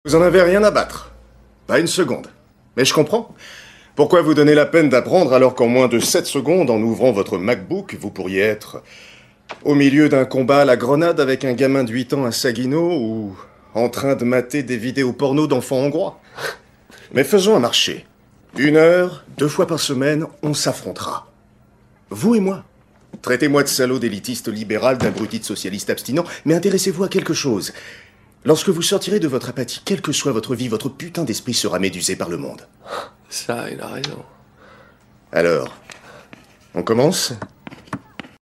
Doublage de la série "Weeds" : voix cool et amusée
Doublage pour la série « Weeds ».
Pour ce rôle, j’ai utilisé une tonalité médium grave, qui incarne parfaitement ce personnage à la fois gentil, intellectuel, amusé et sarcastique.
Dans « Weeds », j’ai joué sur l’humour et le sarcasme pour incarner ce professeur cool.